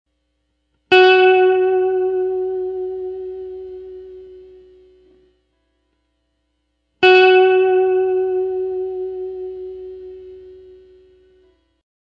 Vibrato
The sound created by vibrato is a slight wavering in the pitch, like a rapid but slight bend-and-release.
The vibrato indicated is played on the second string 5th fret.
vibrato.wav